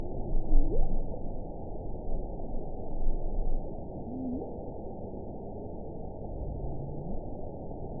event 919327 date 12/30/23 time 18:05:31 GMT (1 year, 4 months ago) score 6.42 location TSS-AB07 detected by nrw target species NRW annotations +NRW Spectrogram: Frequency (kHz) vs. Time (s) audio not available .wav